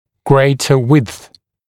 [‘greɪtə wɪdθ][‘грэйтэ уидс]бòльшая ширина